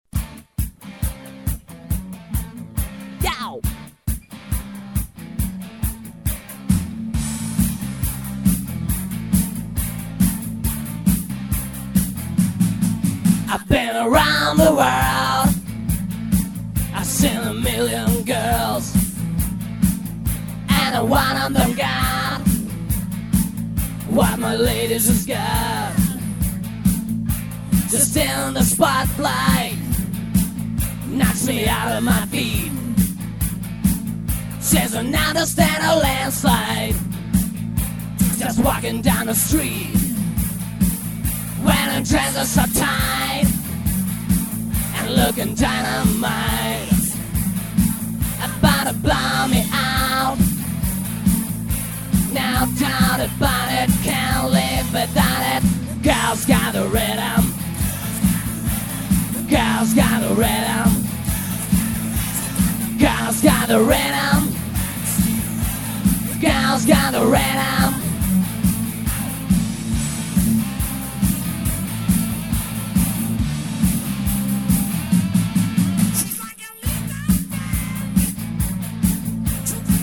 À ÿ è íå êîñèë ïîä íåãî) Ñâîèì ãîëîñîì ñïåë)